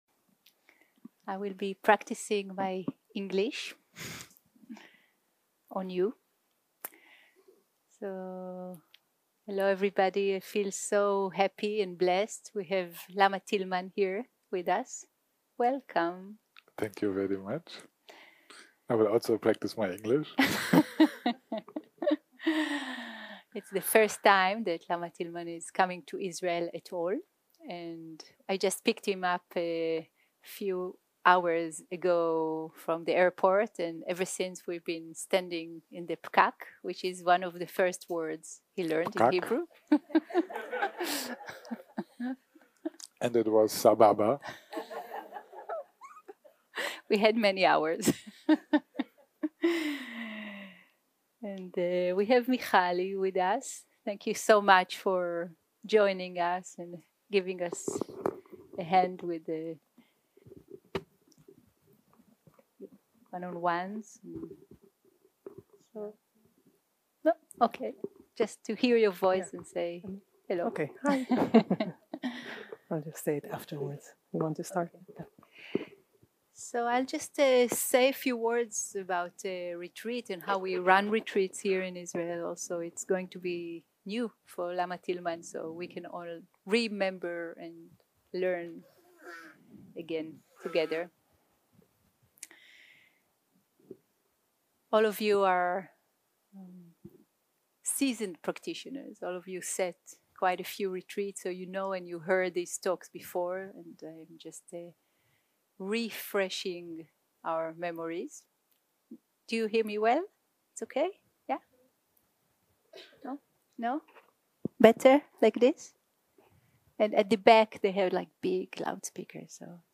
Dharma type: Opening talk שפת ההקלטה